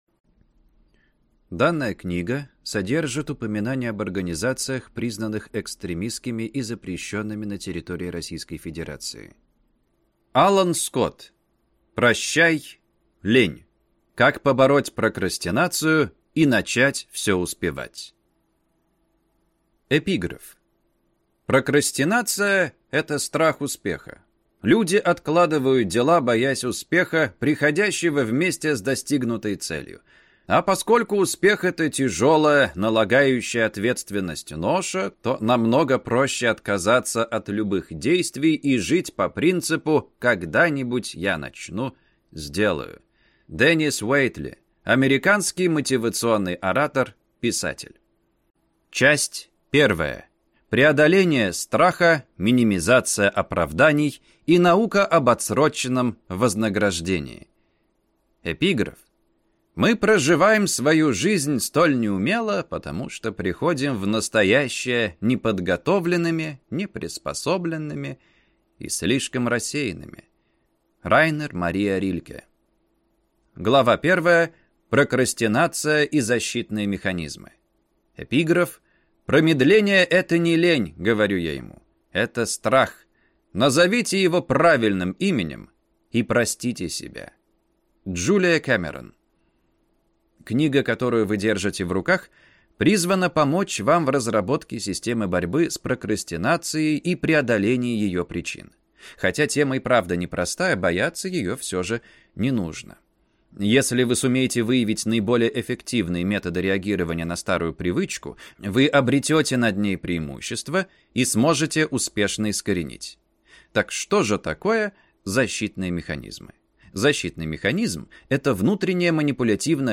Аудиокнига Прощай, лень! Как побороть прокрастинацию и начать все успевать | Библиотека аудиокниг